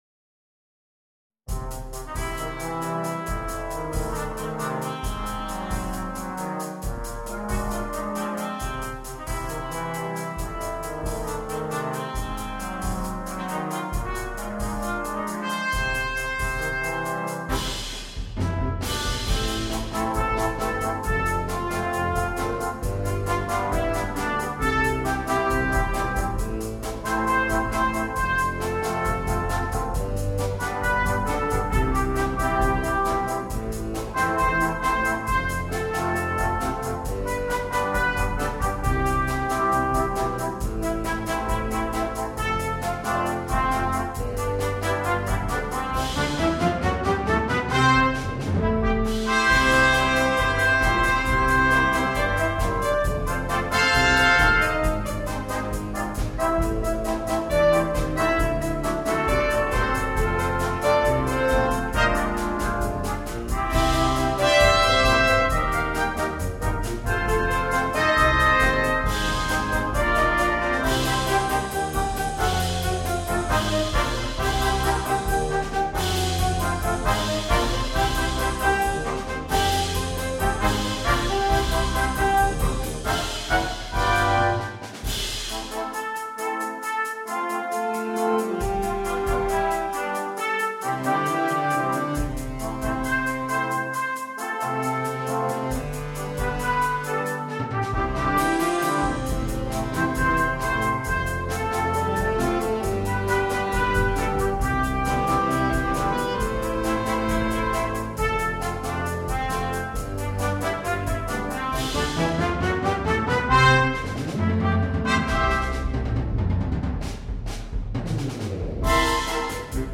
биг-бэнд